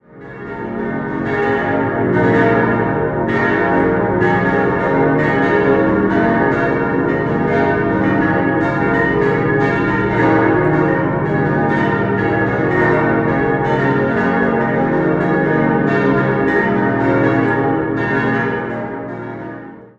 Dabei kam es zu einer tiefgreifenden Um- und Neugestaltung des Innenraums. 6-stimmiges Geläute: b°-des'-es'-f'-as'-b' Alle sechs Glocken wurden bereits im Jahr 1947 von Karl Czudnochowsky gegossen und bilden das erste große Euphongeläut aus der Erdinger Werkstatt.